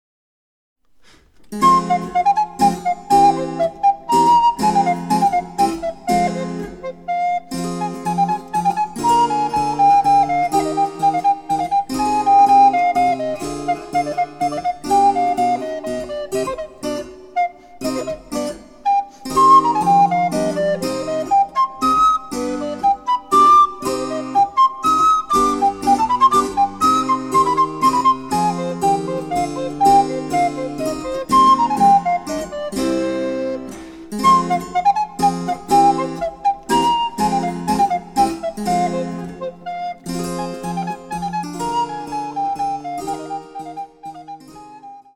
★アルトリコーダー用の名曲をチェンバロ伴奏つきで演奏できる、「チェンバロ伴奏ＣＤブック」です。
★チェンバロ伴奏の演奏には本物のチェンバロ（フレンチ・２段）を使っています。
(1)各楽章につきモダンピッチ(A=440Hz)の伴奏
(3)上記各伴奏につきリコーダーの演奏を合わせた演奏例